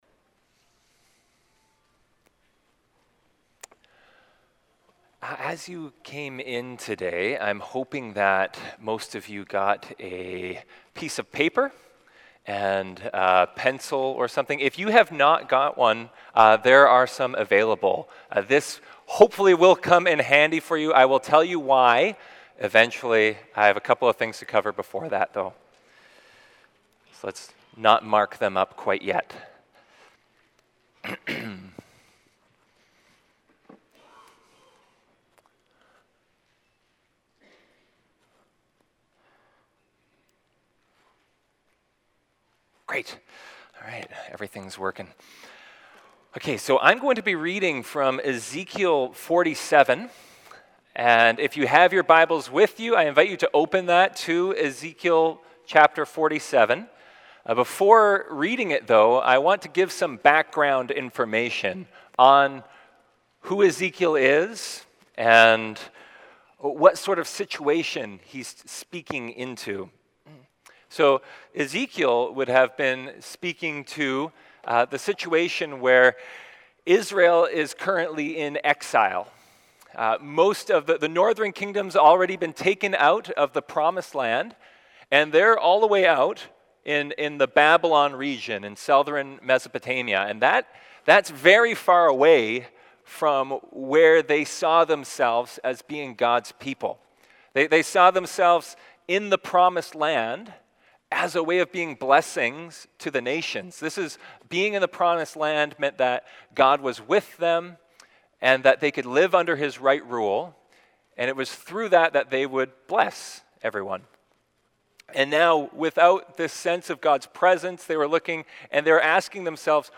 Sermons | Langley Immanuel Christian Reformed Church